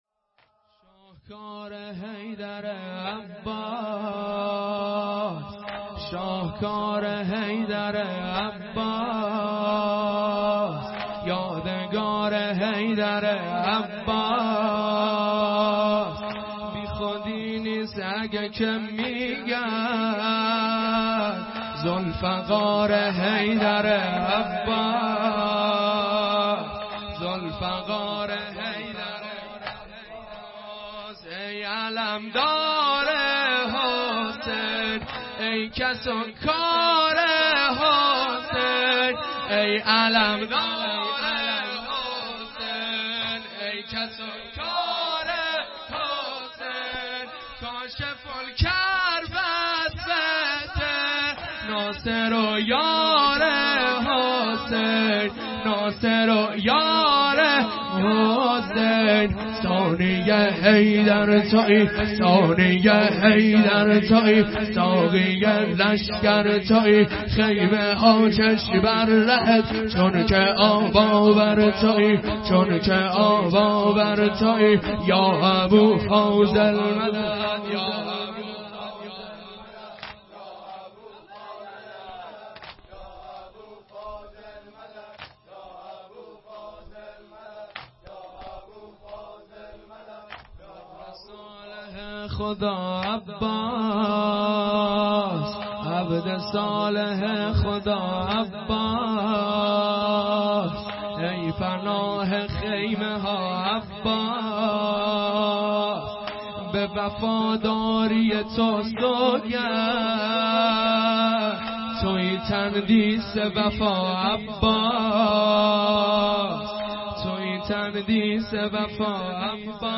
مراسم هفتگی هیئت کف العباس (ع) دزفول